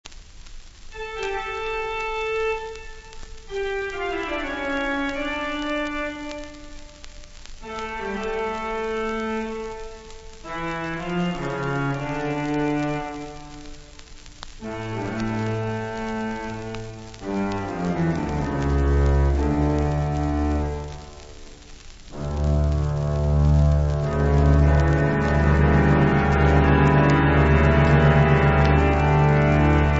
Sul grande organo della Cattedrale di Frejus